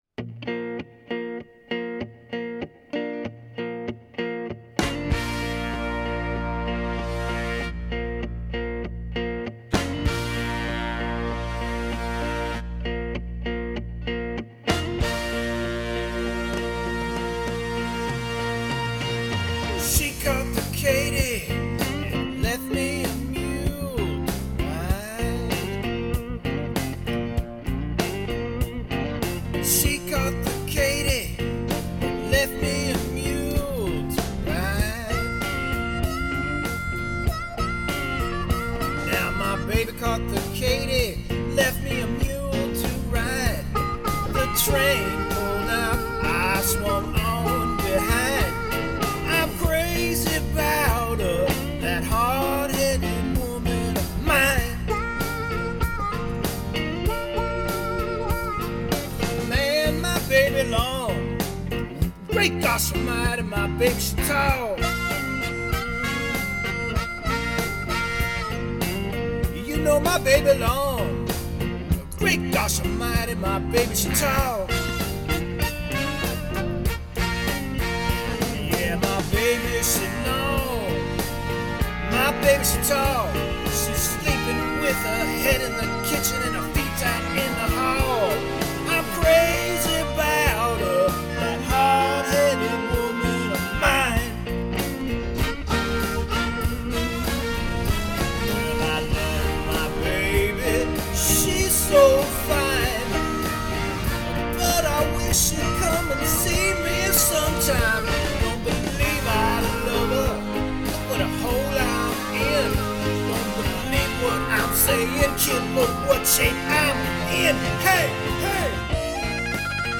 In this case I went with a Honda Odyssey minivan.